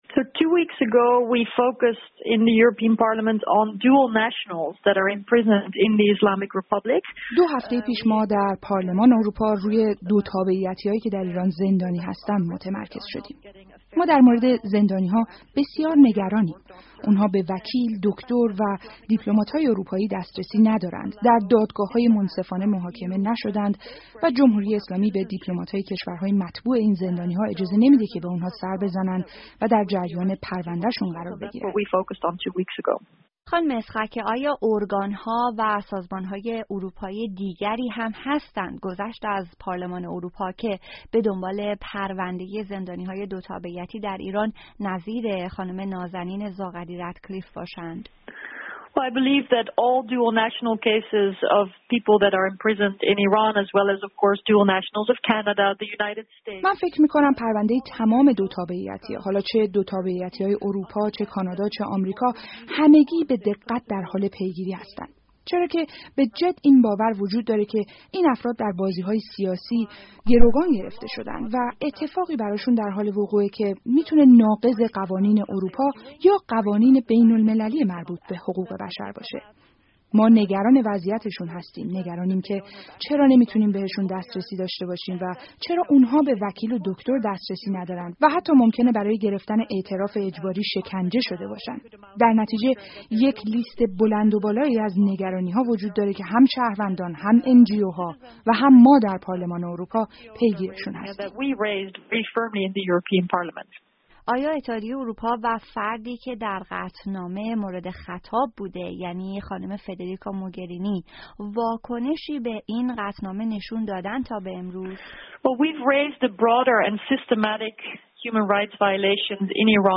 پارلمان اروپا در خرداد ماه با صدور قطعنامه‌ای از ایران خواست تا زندانیان دو تابعیتی محبوس در ایران را آزاد کند. دراین رابطه رادیو فردا با ماری‌یته اسخاکه، نماینده هلند در پارلمان اروپا، گفت‌وگویی انجام داده است.